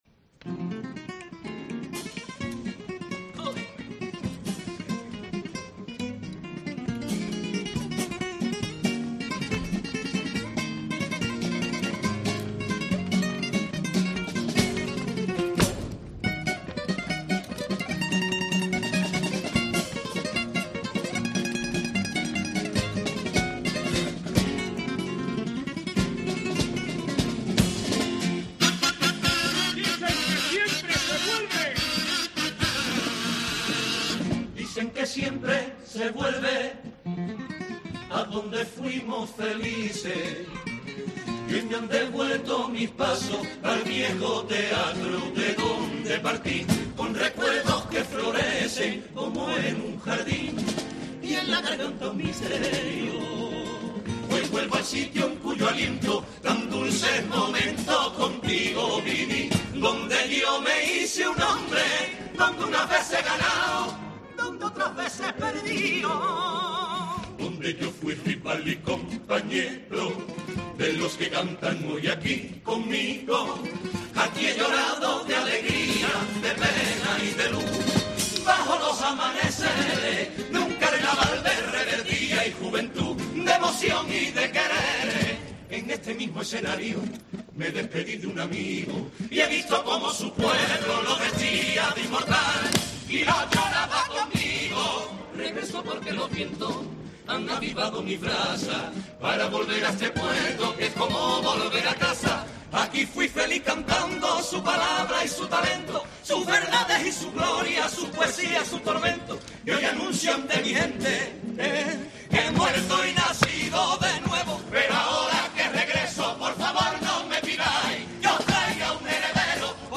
Escucha algunas de las letras que han marcado el Concurso de Agrupaciones del Gran Teatro Falla y que no han dejado indiferente a nadie